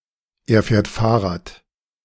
Listen to the two audio clips to compare the pronunciation of “ich fahre” without an Umlaut to “er fährt” with an Umlaut.